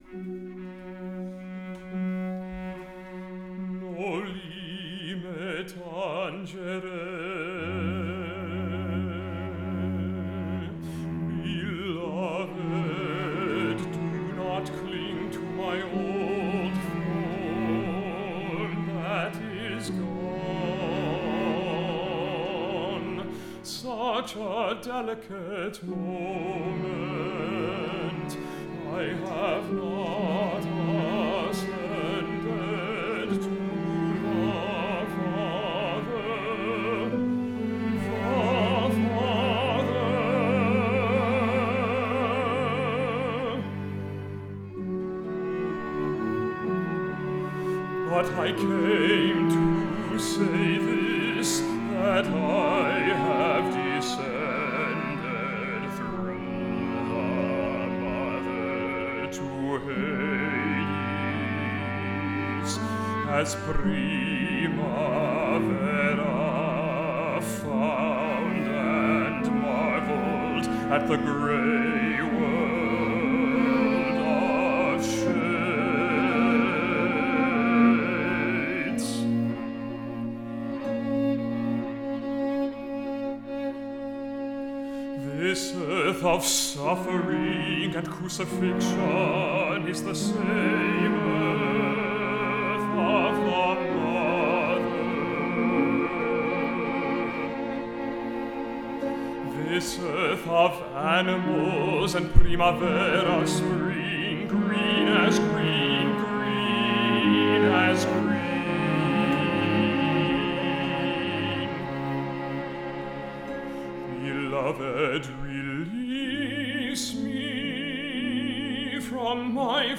Theme: Sacred Music
Category: Music Theatre
a chamber opera
Using a unique mix of classicism and the avant garde